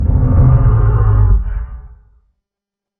Monster Growl
A low, menacing monster growl building from a rumble to a threatening snarl
monster-growl.mp3